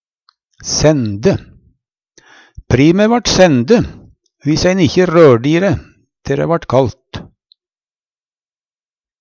sende - Numedalsmål (en-US)